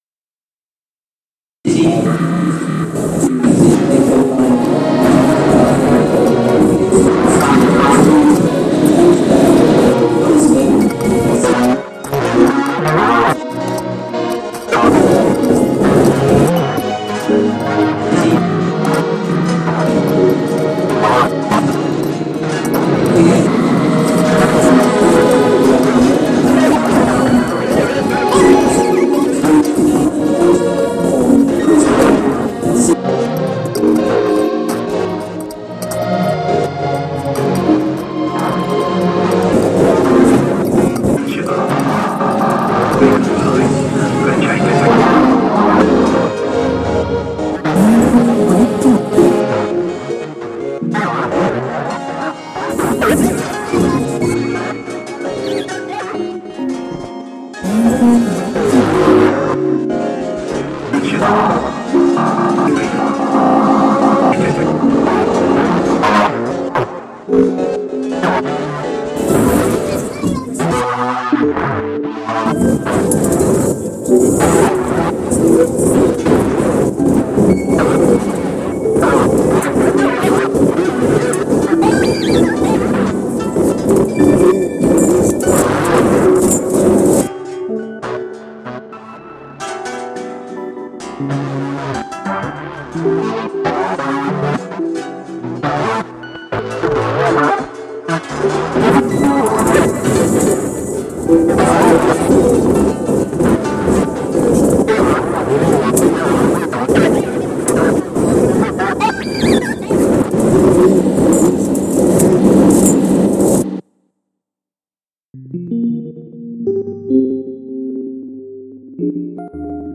Part of our project The Next Station, reimagining the sounds of the London Underground and creating the first ever tube sound map.